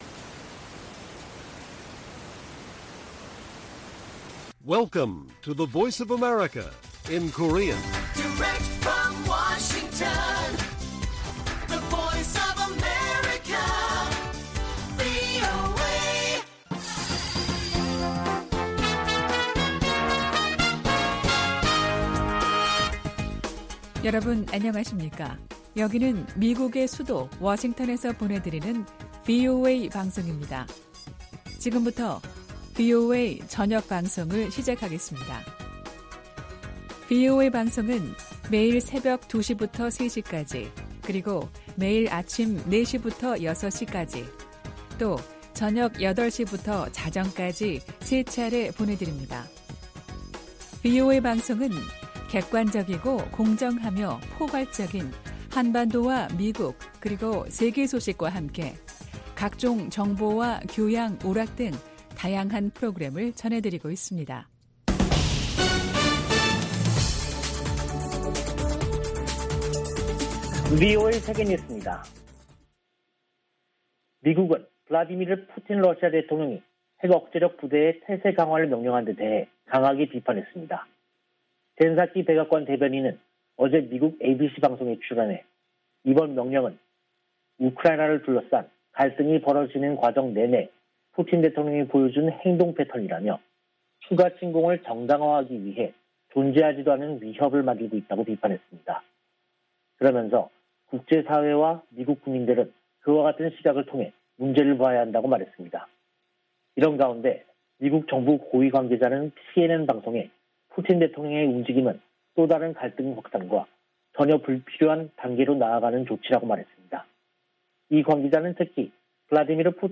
VOA 한국어 간판 뉴스 프로그램 '뉴스 투데이', 2022년 2월 28일 1부 방송입니다. 북한은 27일 발사한 준중거리 탄도미사일이 정찰위성에 쓰일 카메라 성능을 점검하기 위한 것이었다고 밝혔습니다. 미 국무부는 북한의 탄도미사일 시험 발사 재개를 규탄하고 도발 중단을 촉구했습니다. 미한일 외교∙안보 고위 당국자들이 전화협의를 갖고 북한의 행동을 규탄하면서 3국 공조의 중요성을 거듭 강조했습니다.